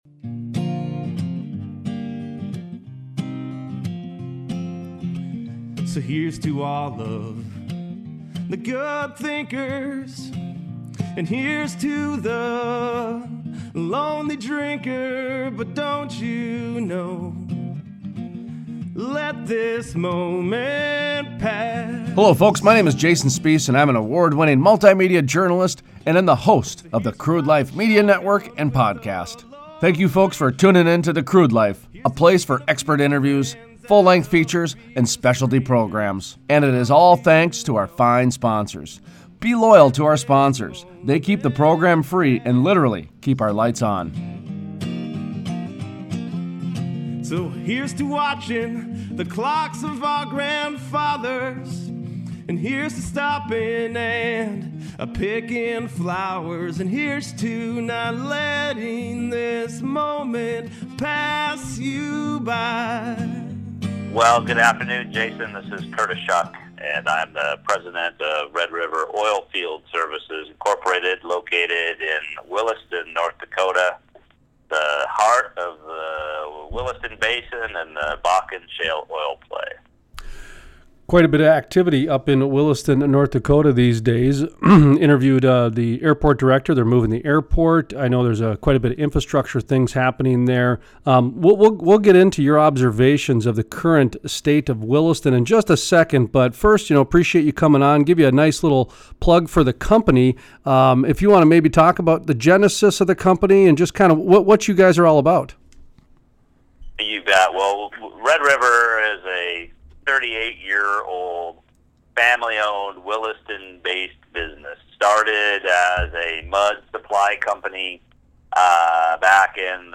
Full Length Interview